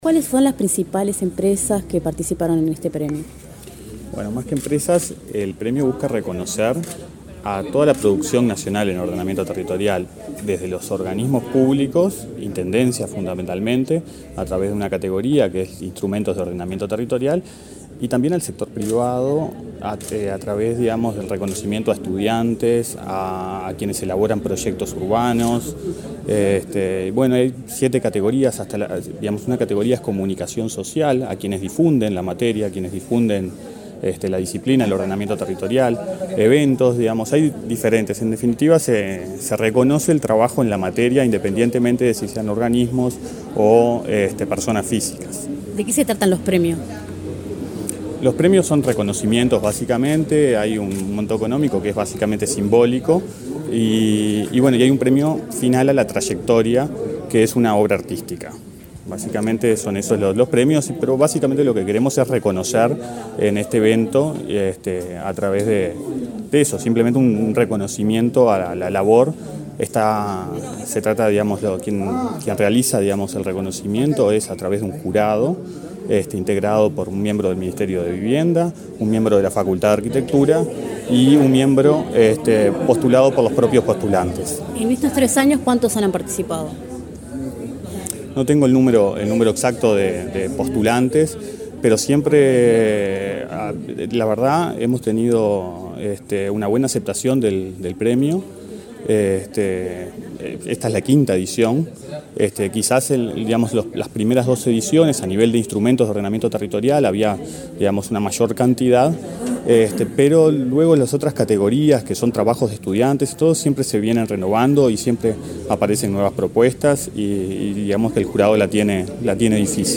Declaraciones del director de Ordenamiento Territorial, José Pedro Aranco